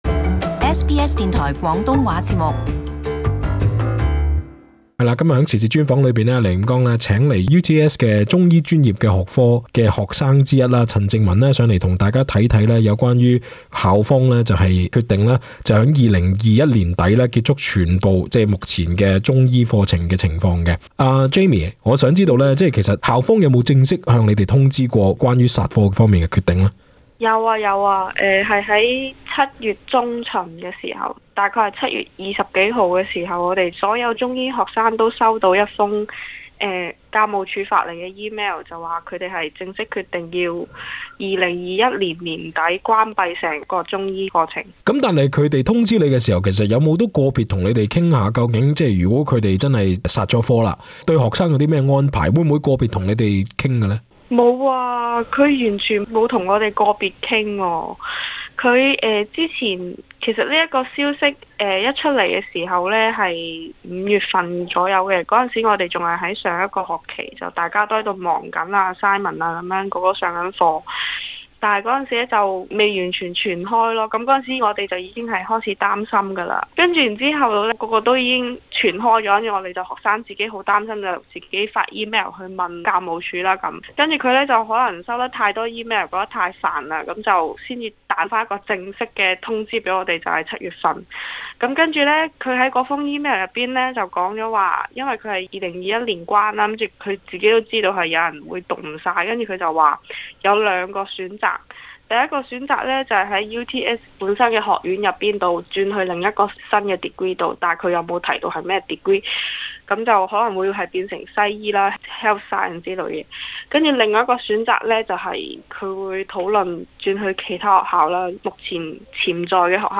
【社區專訪】UTS宣布停辦中醫系 學生前路茫茫